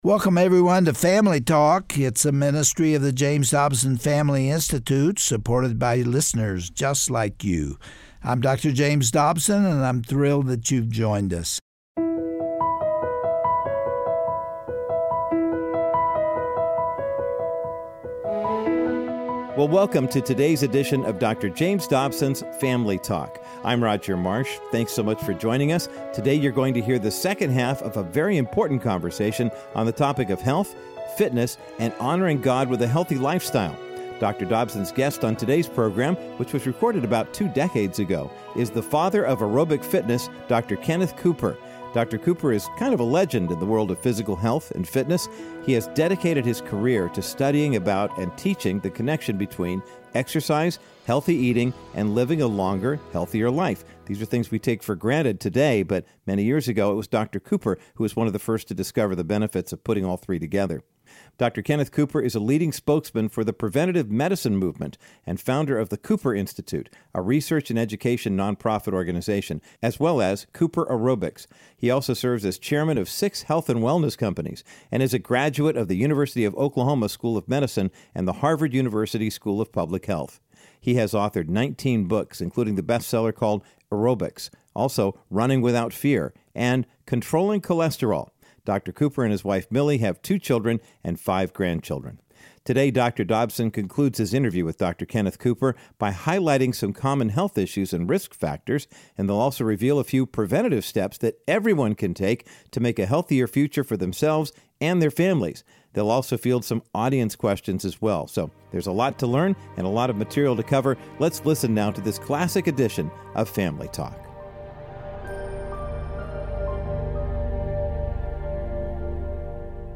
On today’s classic edition of Family Talk, Dr. James Dobson concludes his insightful discussion with Dr. Kenneth Cooper, best-selling author of 19 health books. Dr. Cooper, who is also referred to as the "father of aerobics," offers practical counsel on the importance of maintaining a healthy lifestyle. Learn how you can empower your body to fight against dangerous free radicals, and give yourself a fighting chance to avoid up to 51 different diseases.